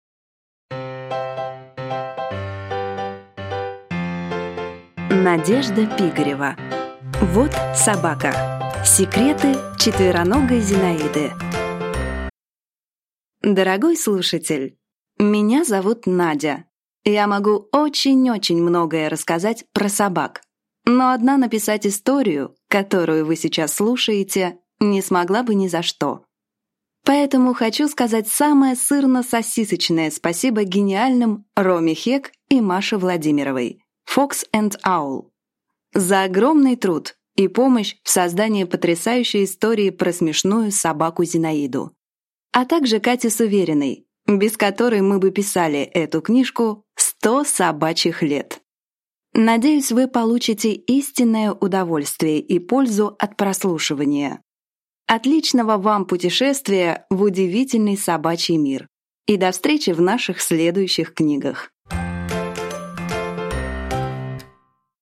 Аудиокнига Вот собака! Секреты четвероногой Зинаиды | Библиотека аудиокниг